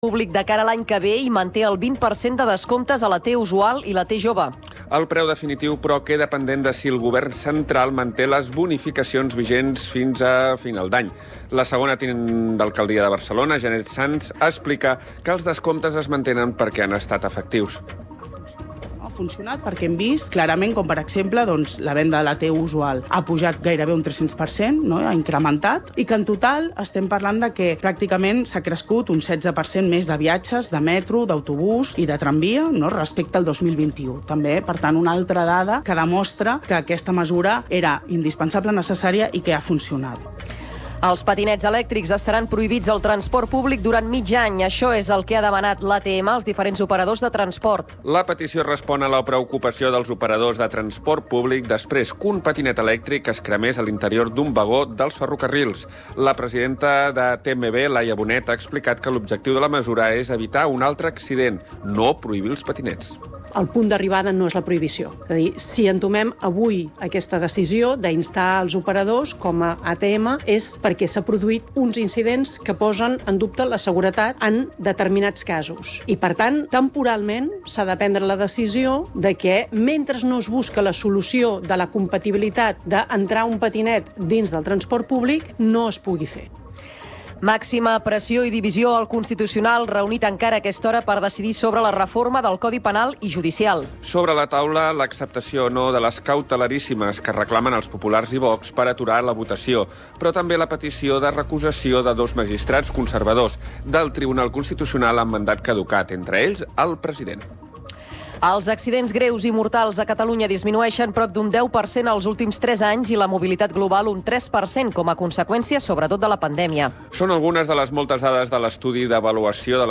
Informatius Notícies en xarxa (edició vespre)